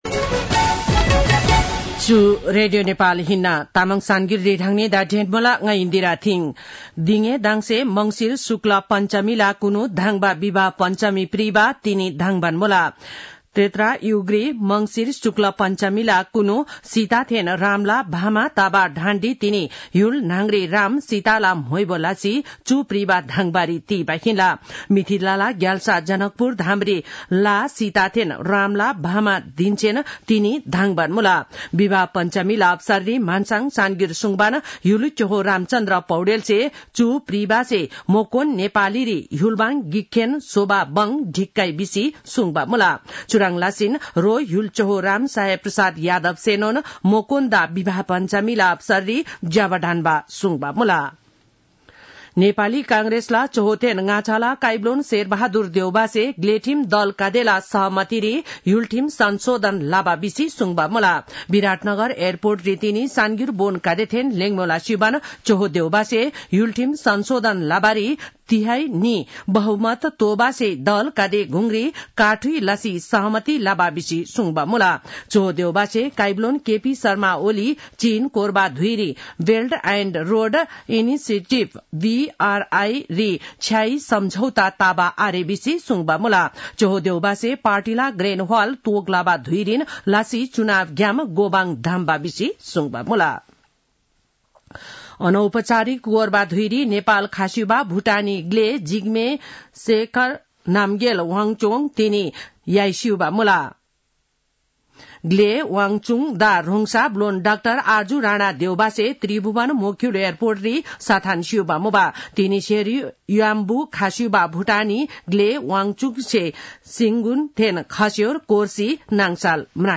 An online outlet of Nepal's national radio broadcaster
तामाङ भाषाको समाचार : २२ मंसिर , २०८१
Tamang-news-8-21.mp3